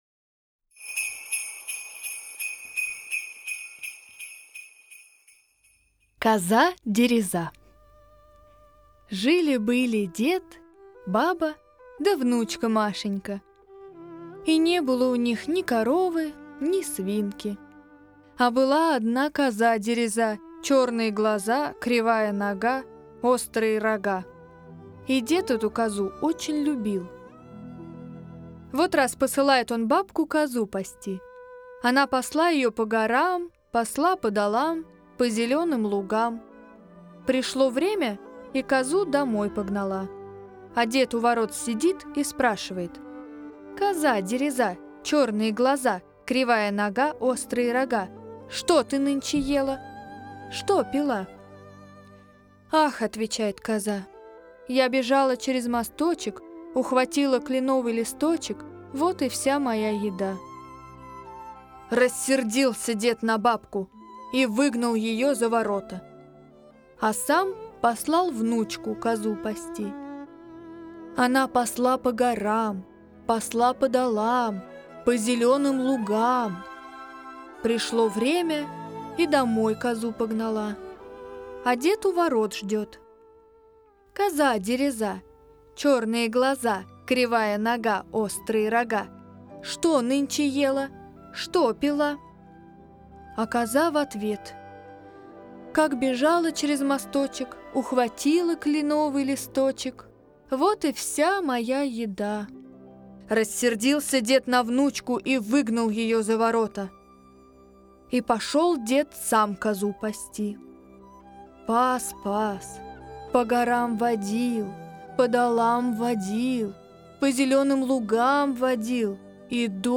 Аудиосказки Русские народные Коза-дереза